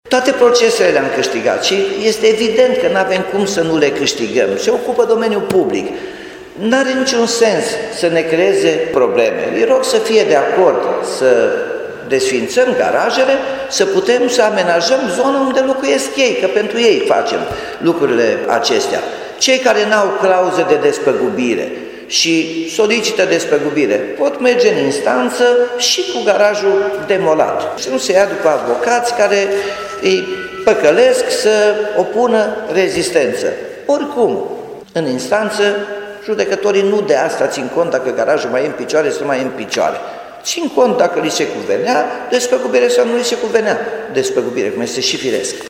Persoanele care refuză demolarea, vor fi obligate să se adreseze instanţei, spune primarul Nicolae Robu.